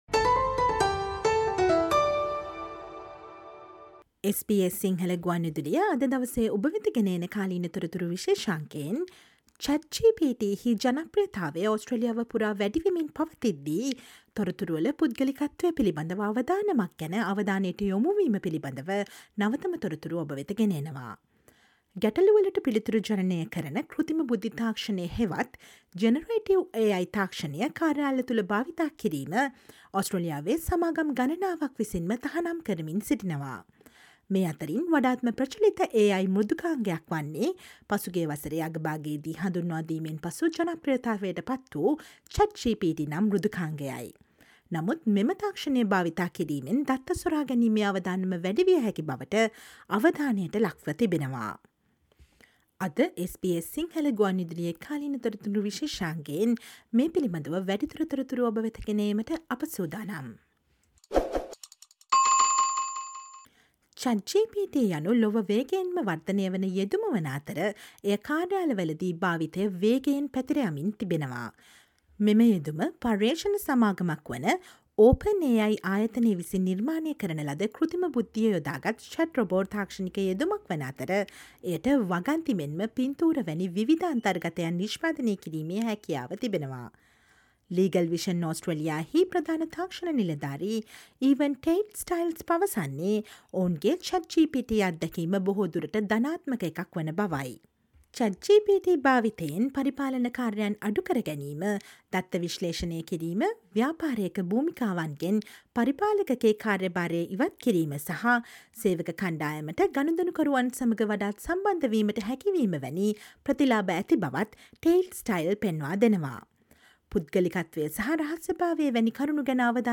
Listen to the SBS Sinhala radio current affair feature for the latest updates on why Australian organisations are banning generative AI chatGPT.